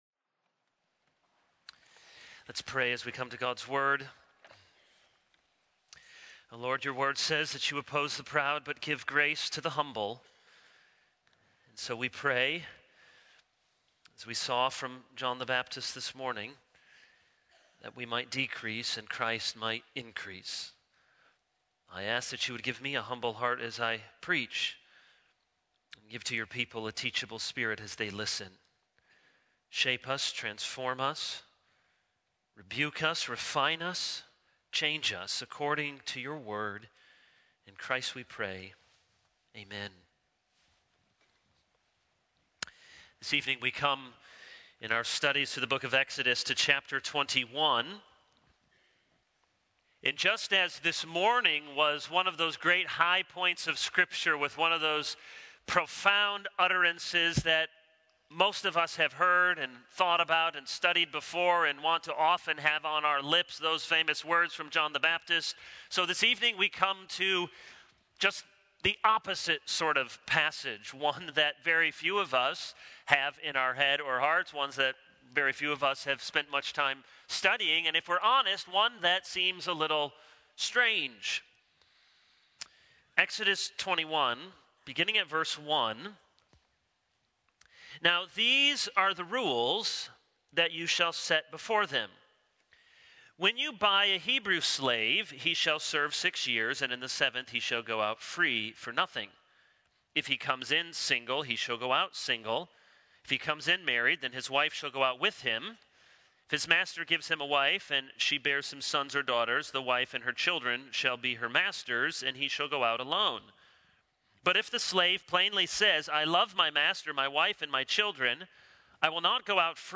This is a sermon on Exodus 21:1-11.